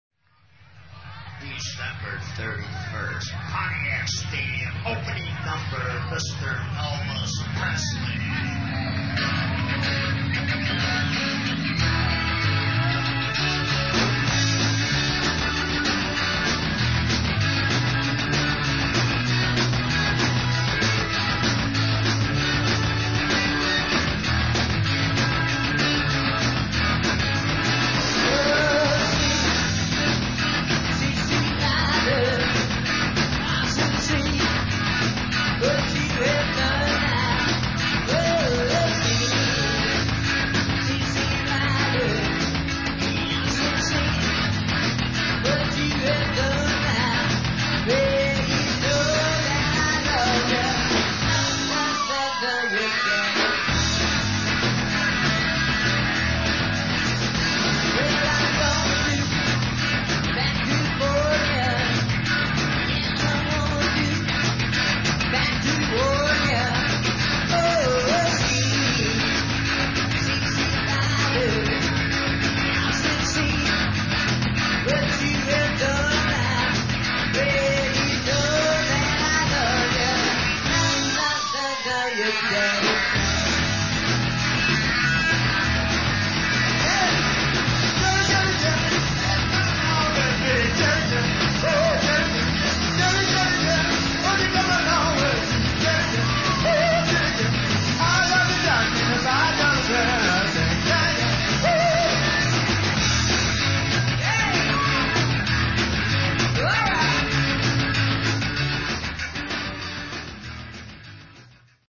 Grand Rapids' Grandest Garage Band